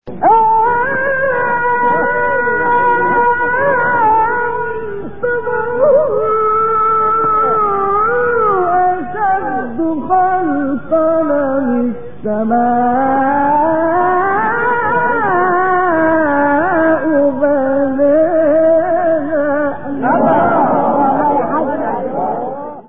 گروه شبکه اجتماعی: مقاطعی صوتی از تلاوت قاریان برجسته مصری ارائه می‌شود.